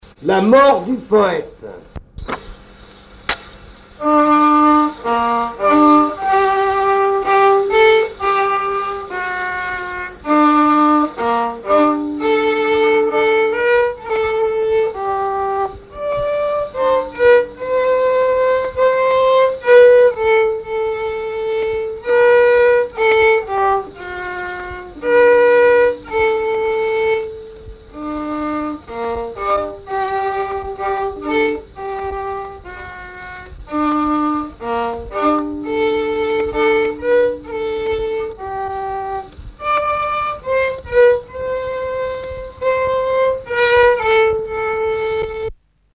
Genre strophique
recherche de répertoire de violon pour le groupe folklorique
Pièce musicale inédite